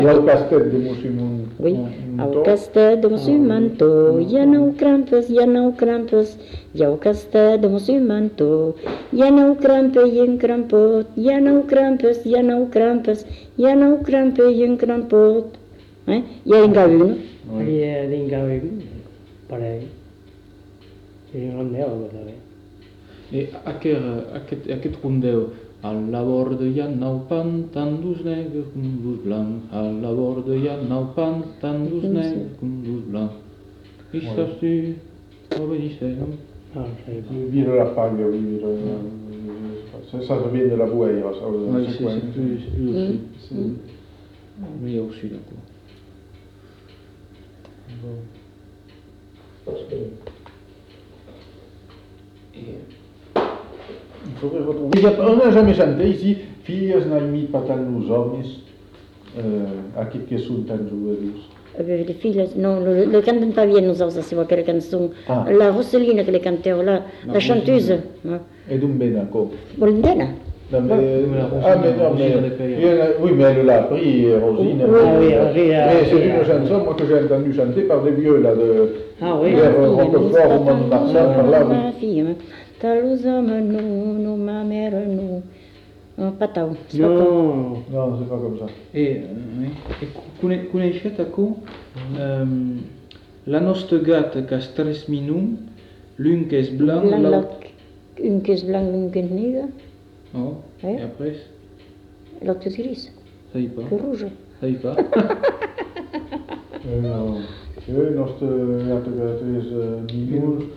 Aire culturelle : Bazadais
Lieu : Cazalis
Genre : chant
Effectif : 1
Type de voix : voix de femme
Production du son : chanté
Danse : congo
Classification : chansons de neuf